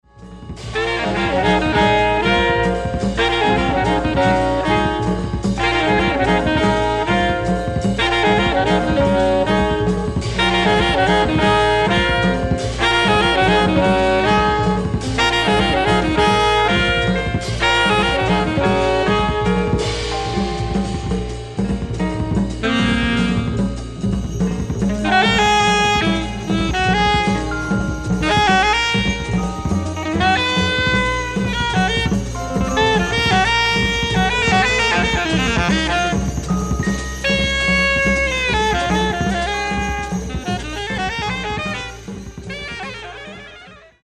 フリー/レアグルーヴ/フレンチ・ジャズ